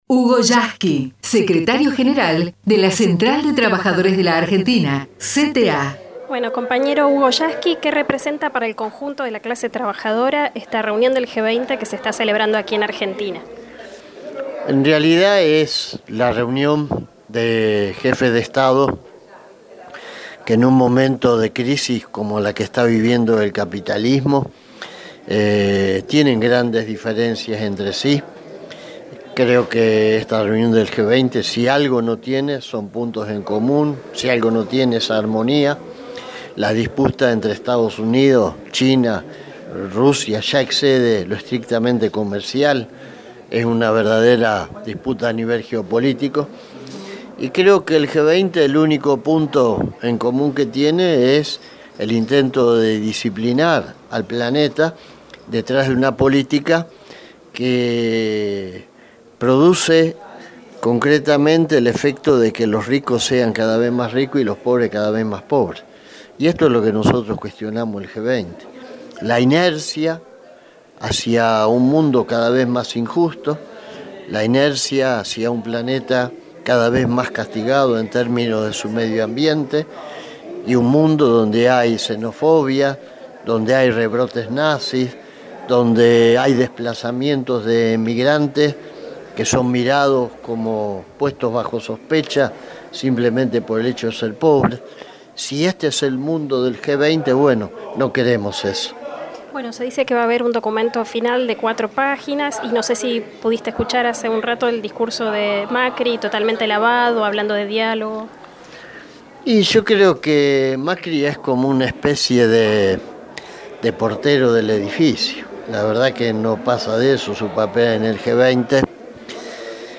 NO AL G-20 // HUGO YASKY - marcha en Buenos Aires